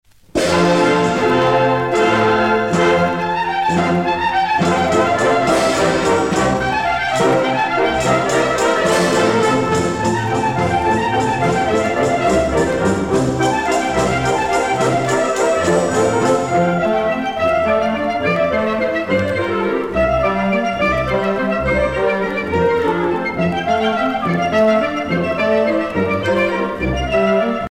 grande jota
Pièce musicale éditée